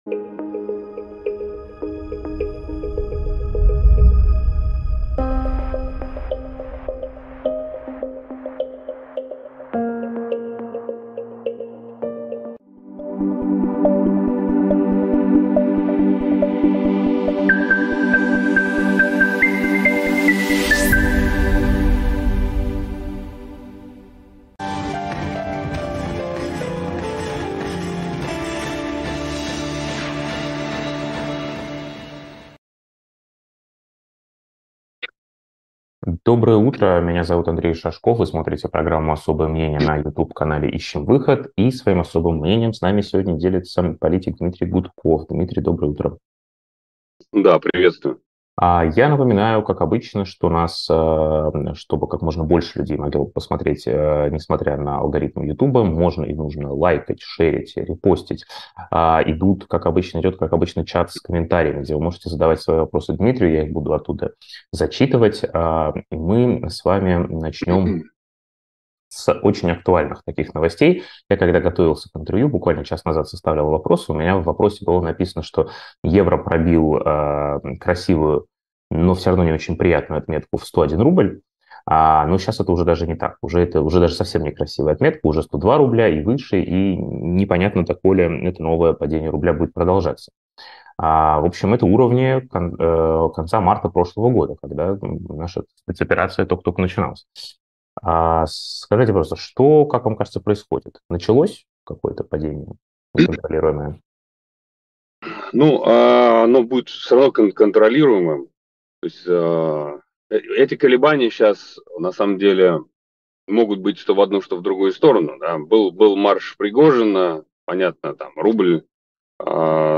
Как обычно, идет чат с комментариями, где вы можете задавать свои вопросы Дмитрию.